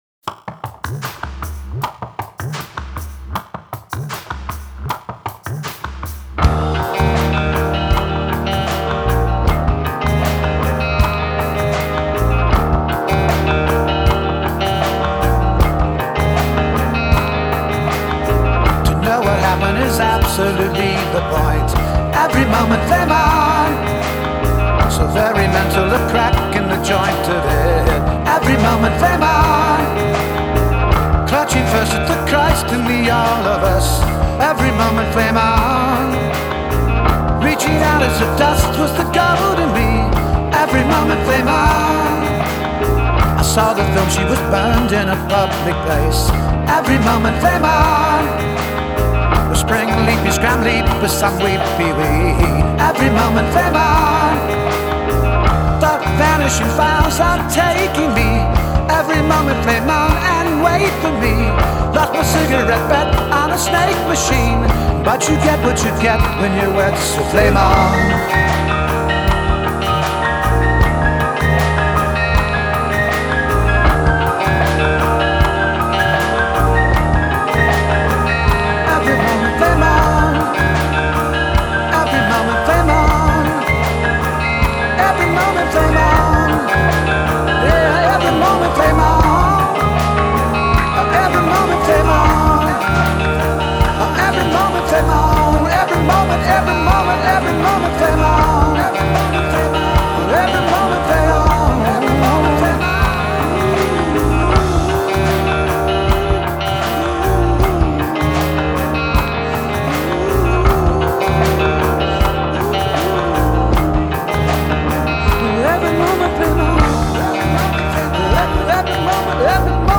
moods ranging from jubilant to melancholy to mean